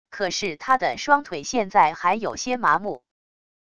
可是他的双腿现在还有些麻木wav音频生成系统WAV Audio Player